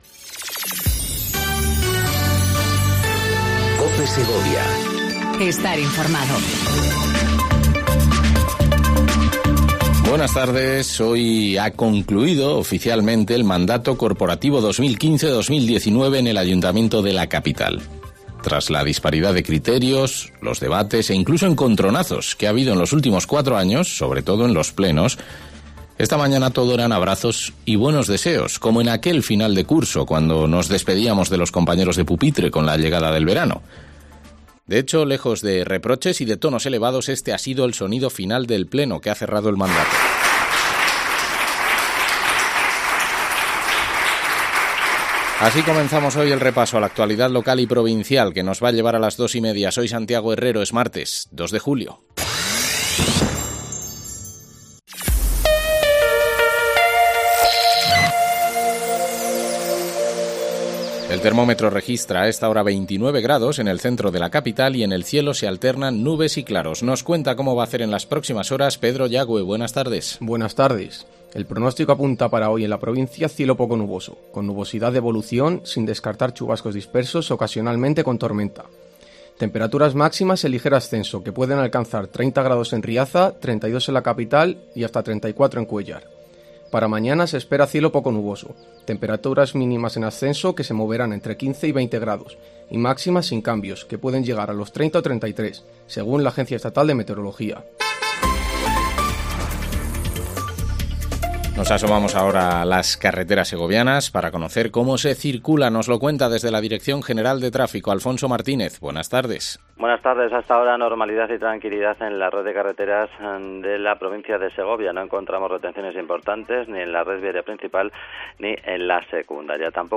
INFORMATIVO DEL MEDIODÍA EN COPE SEGOVIA 14:20 DEL 02/07/19